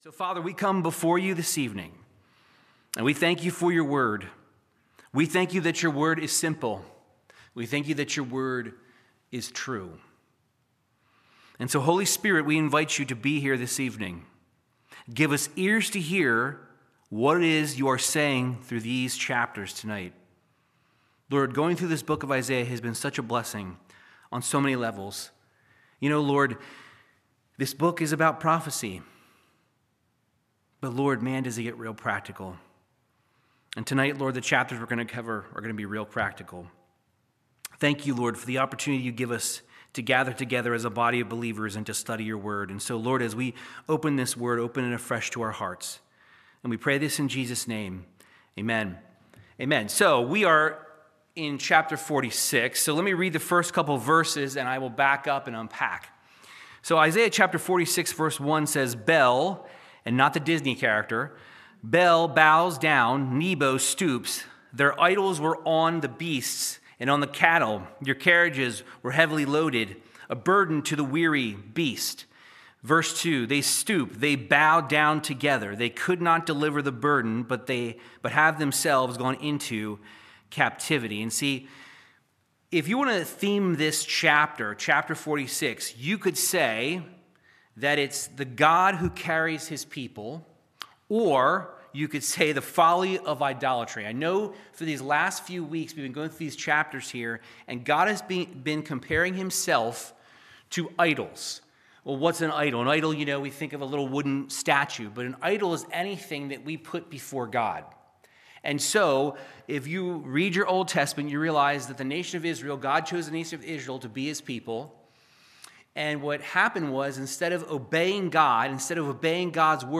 Verse by verse Bible teaching through Isaiah chapters 46 through 48